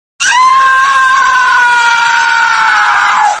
Luigi Screaming Sound Effect Free Download
Luigi Screaming